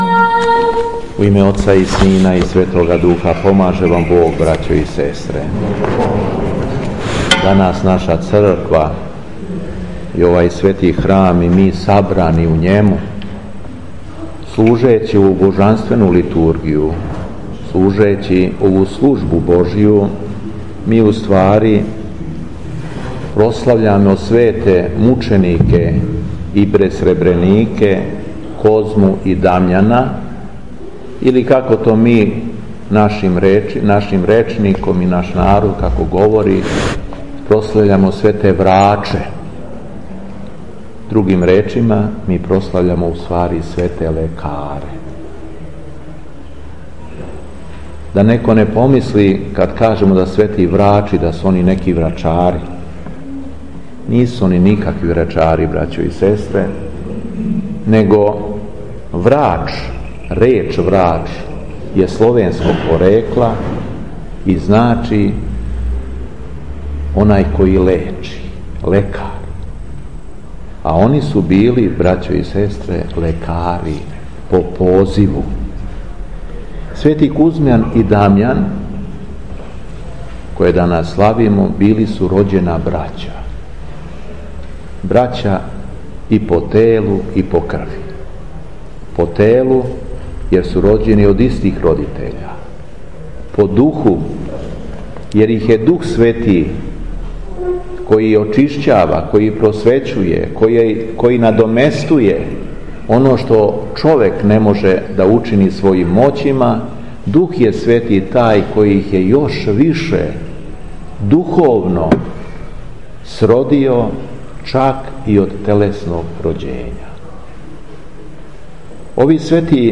У суботу, 14. јула 2018. године, када наша Света Црква прославља Свете бесребренике и чудотворце Козму и Дамјана, Његово Преосвештенство Епископ шумадијски Господин Јован служио је Свету Архијерејску Литургију у селу Загорици код Тополе.
Беседа Епископа шумадијског Г. Јована